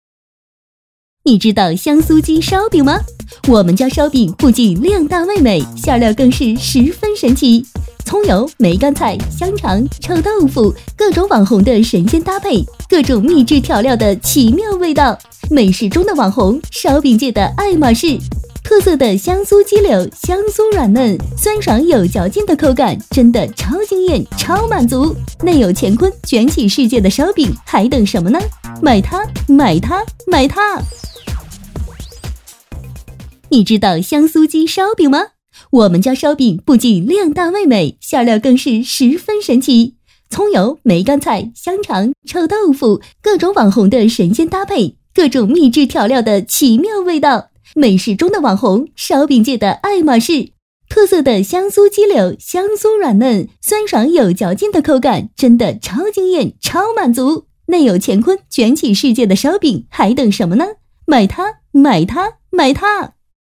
女220-还有人没吃过香酥广告
女220专题广告 v220
女220-还有人没吃过香酥广告.mp3